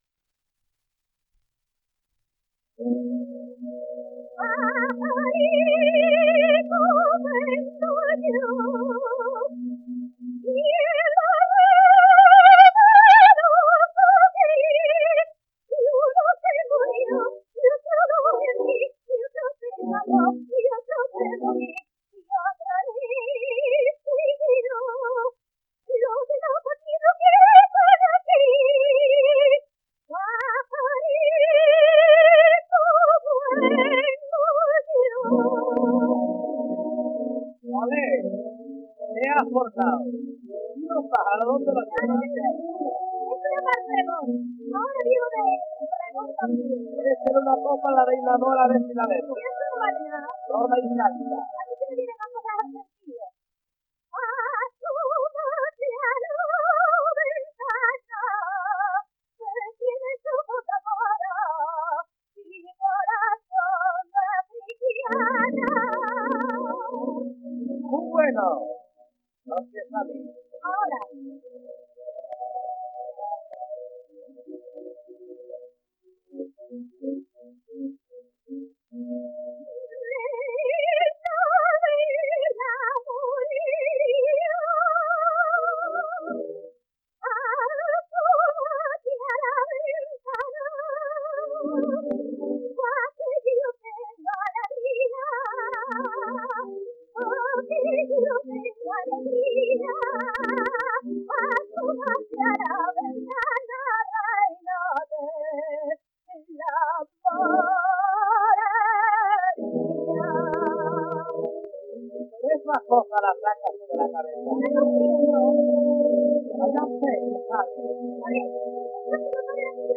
1 disco : 78 rpm ; 27 cm Intérpretes